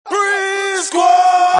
Tm8_Chant45.wav